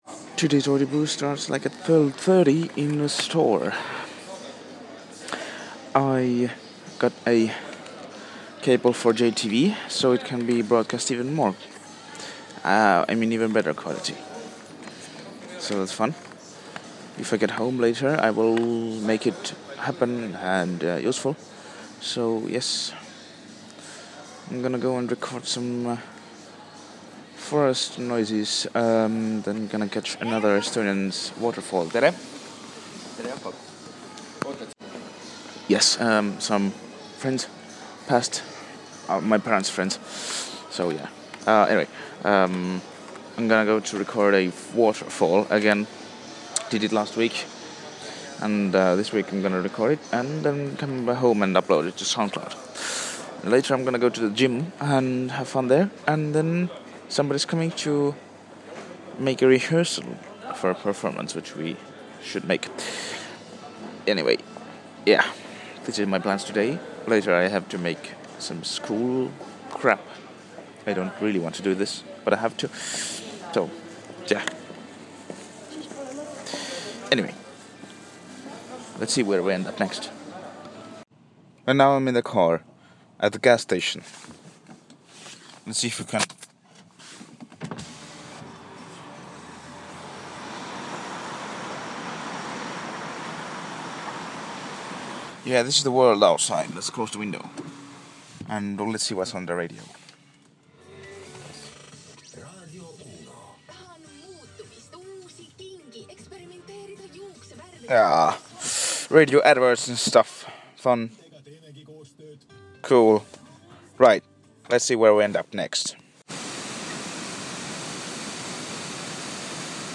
The waterfall